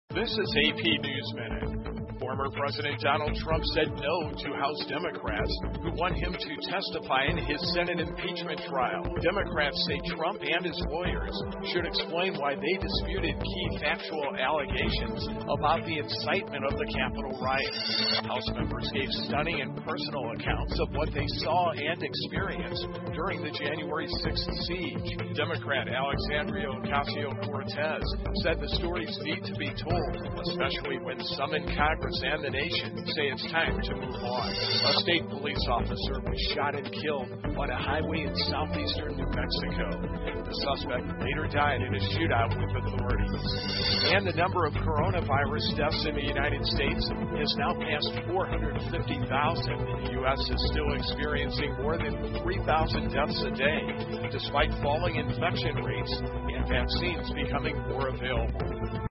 美联社新闻一分钟 AP 美国新冠病毒死亡人数破45万 听力文件下载—在线英语听力室